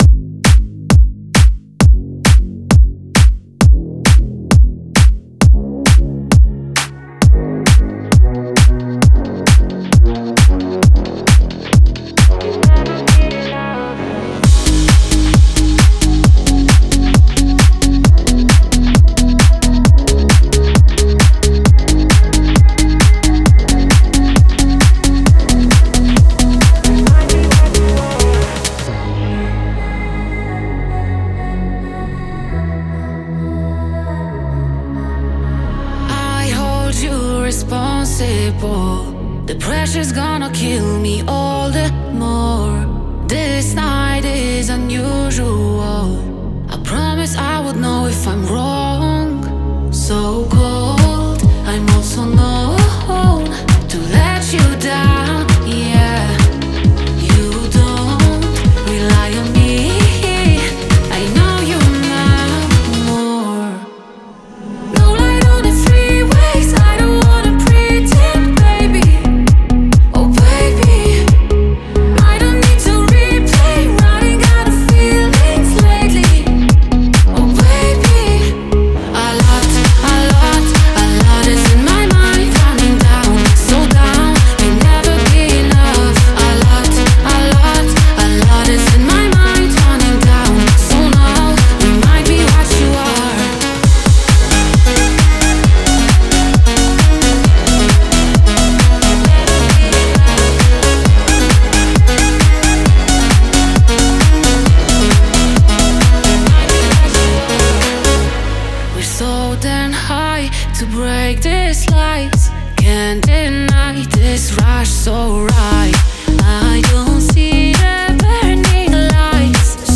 klubowej, zachwycającej kolaboracji!